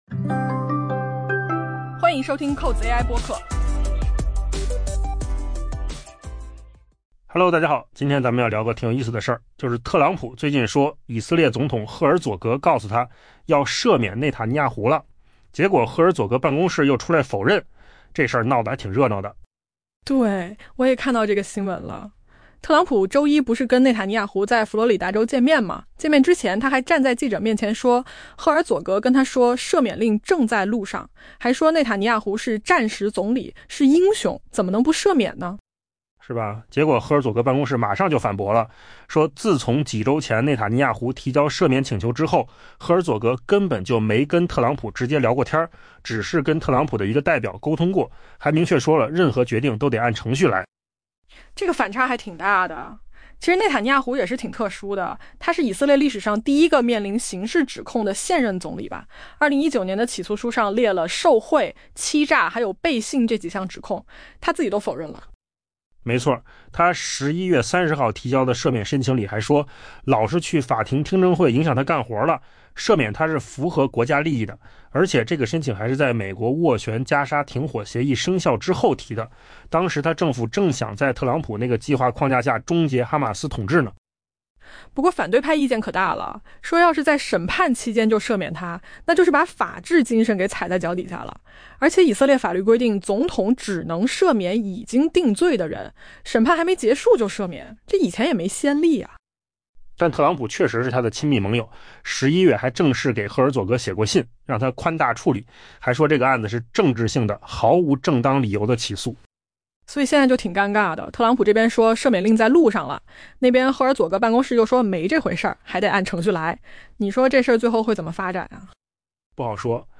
AI播客：换个方式听新闻 下载mp3
音频扣子空间生成